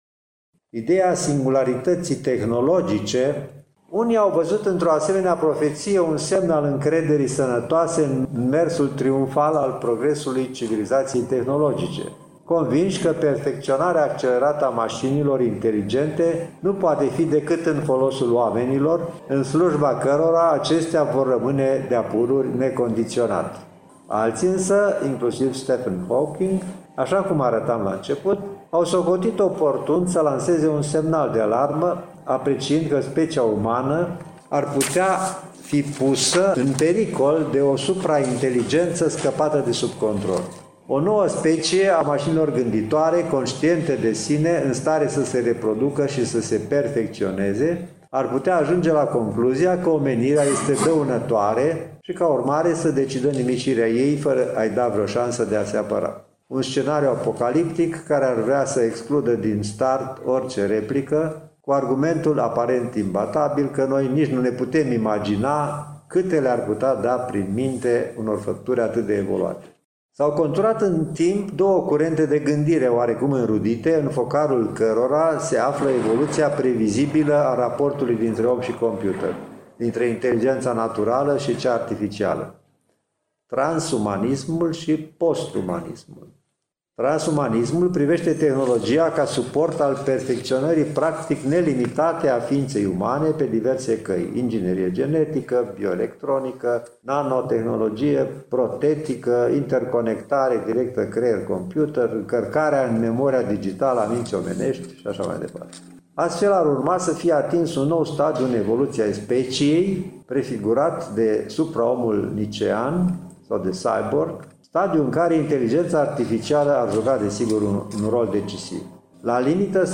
Înregistrarea a fost făcută în cadrul unei dezbateri publice, dedicate, Președinției, Consiliului Uniunii Europene.
În avanpremieră, vă prezentăm un fragment din expunerea domniei sale.